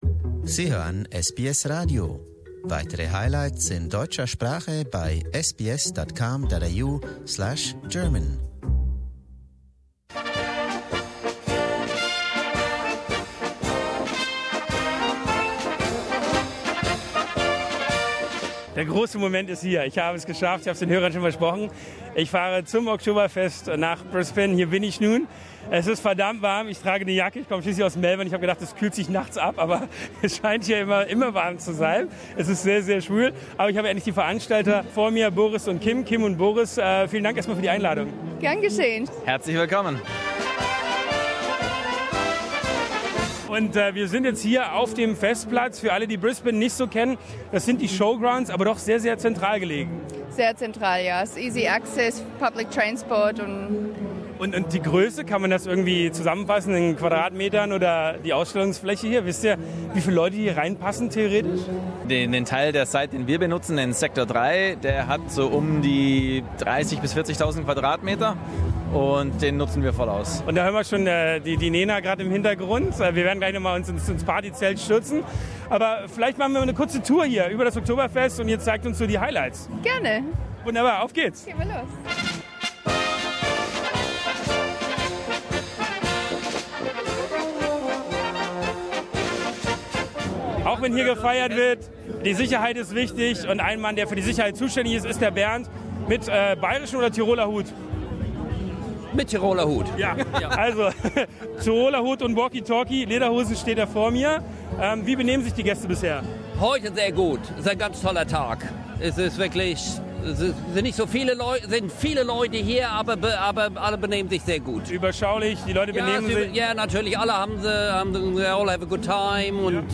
O'zapft heißt es auch in Brisbane jedes Jahr, wenn rund 40.000 Besucher der bayrischen Kultur fröhnen. SBS German war mit dem Mikro vor Ort, um Musik, Stimmung und australische Begeisterung live einzufangen.